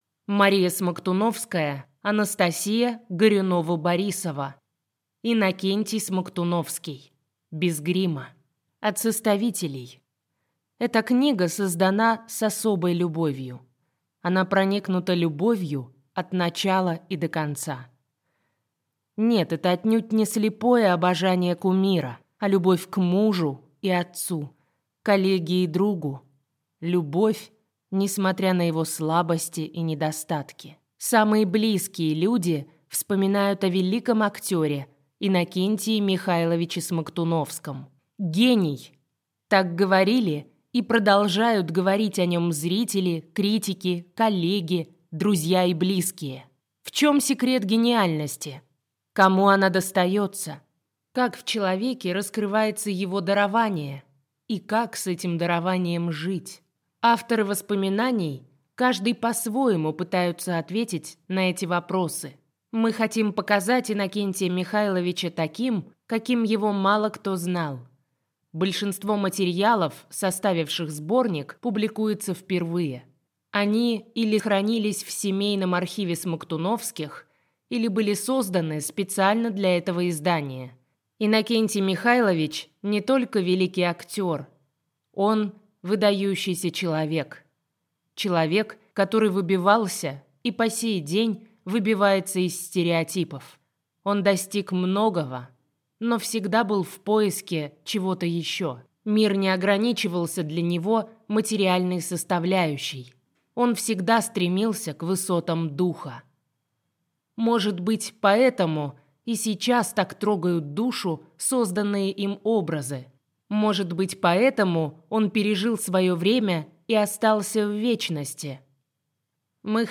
Аудиокнига Иннокентий Смоктуновский. Без грима | Библиотека аудиокниг